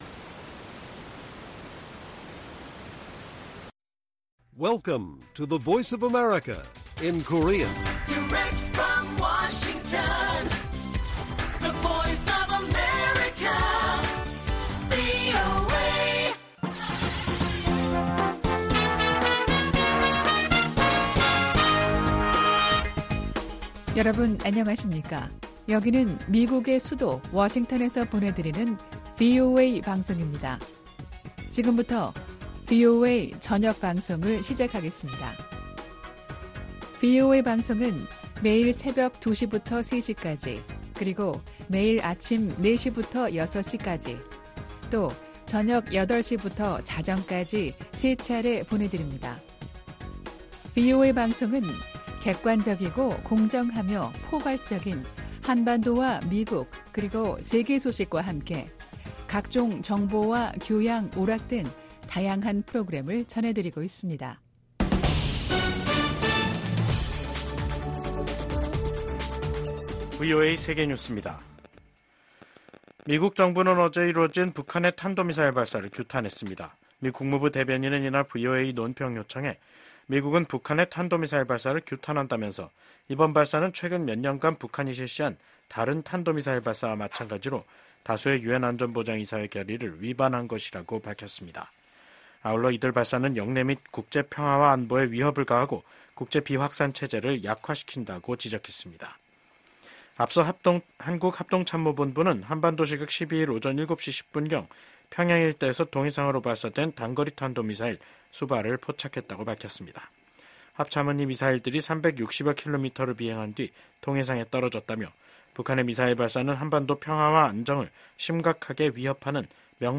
VOA 한국어 간판 뉴스 프로그램 '뉴스 투데이', 2024년 9월 12일 1부 방송입니다. 북한이 70여일 만에 또 다시 단거리 탄도미사일 도발에 나섰습니다.